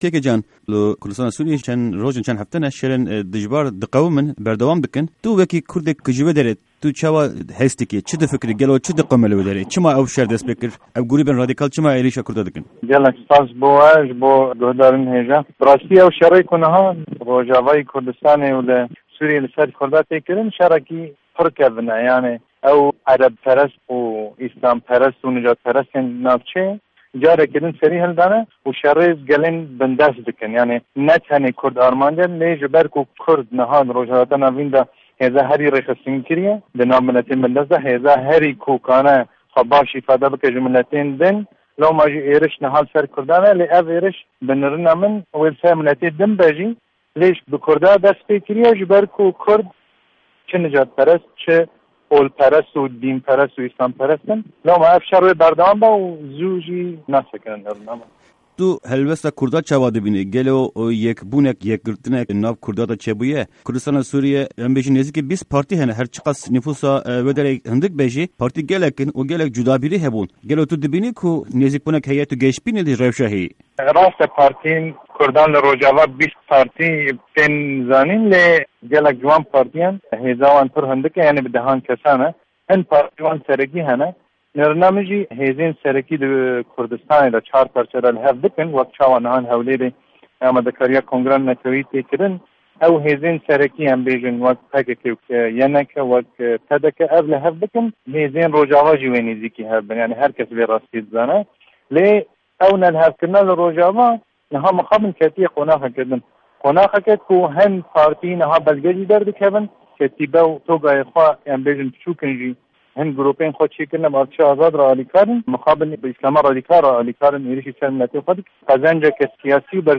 di hevpeyvîna Pişka Kurdî ya Dengê Amerîka de ser şerê li Kurdistana Sûrîyê dinirxîne û sedemên êrîşên dijî Kurdan şîrove dike.